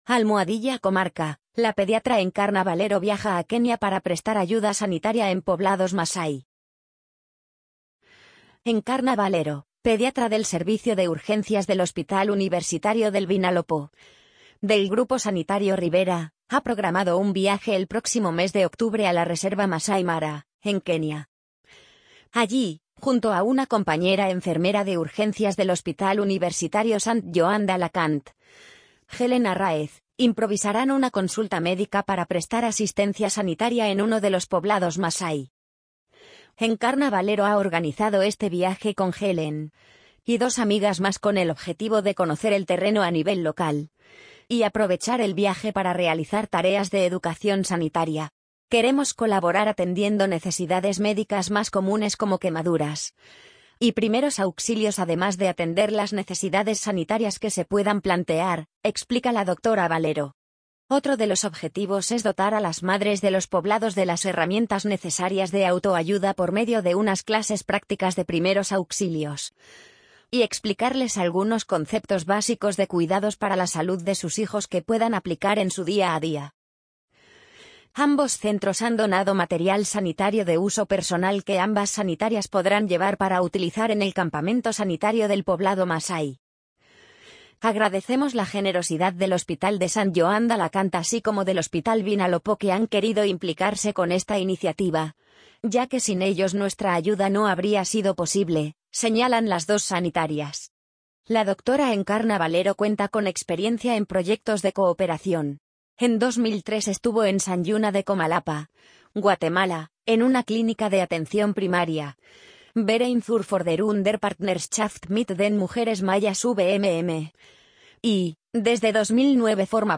amazon_polly_68359.mp3